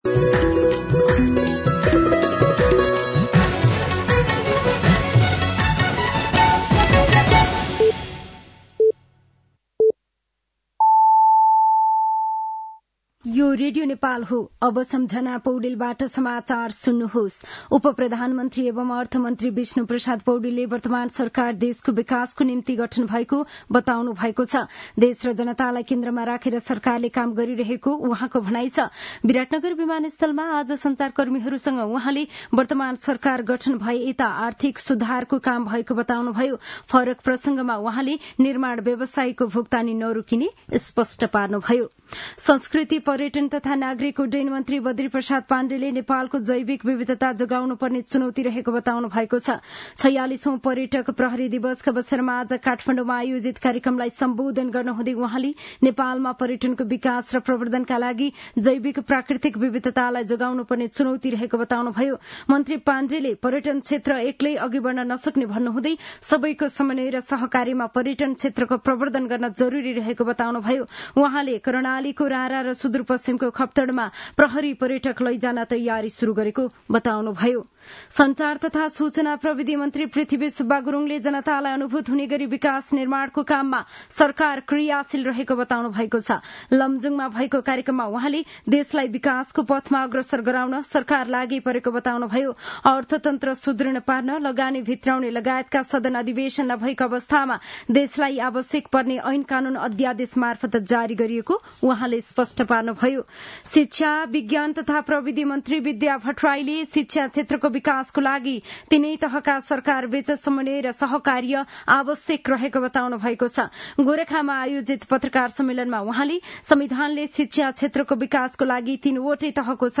साँझ ५ बजेको नेपाली समाचार : १३ माघ , २०८१